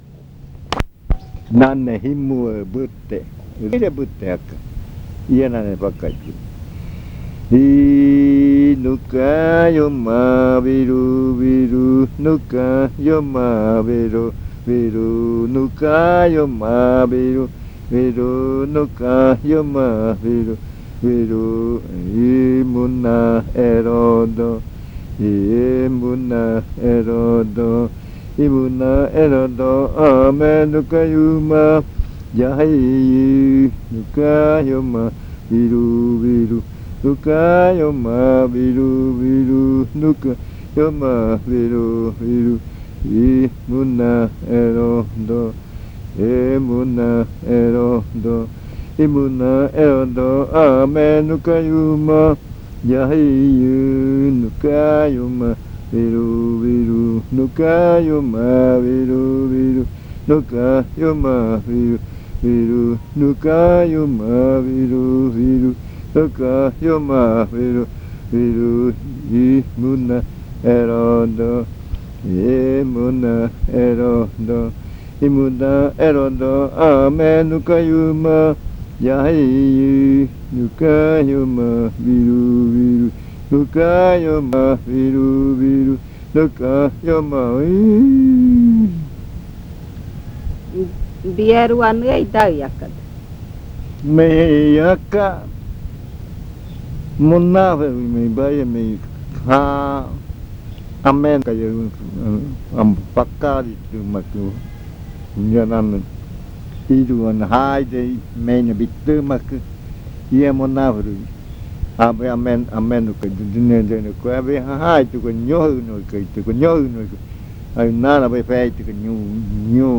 Leticia, Amazonas
Este canto hace parte de la colección de cantos del ritual Yuakɨ Murui-Muina (ritual de frutas) del pueblo Murui
This chant is part of the collection of chants from the Yuakɨ Murui-Muina (fruit ritual) of the Murui people